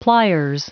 Prononciation du mot pliers en anglais (fichier audio)
Prononciation du mot : pliers